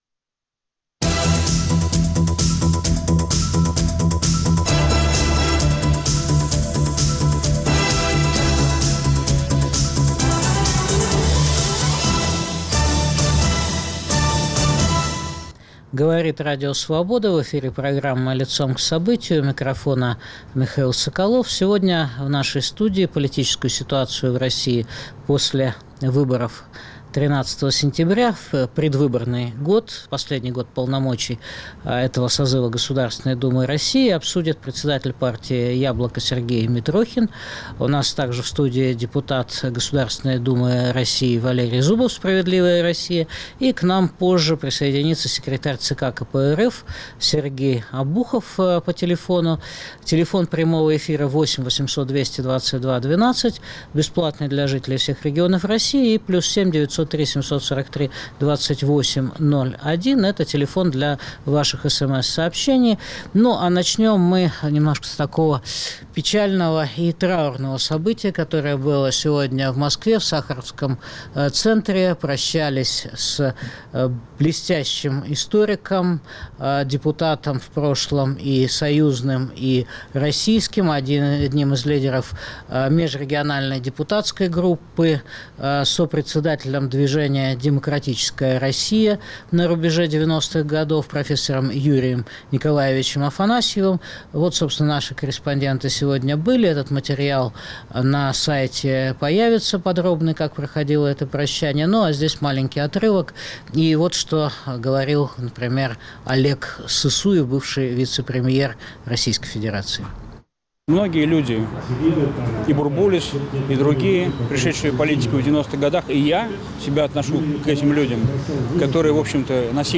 Обсуждают лидер партии "Яблоко" Сергей Митрохин, и депутаты ГД РФ Валерий Зубов, Сергей Обухов.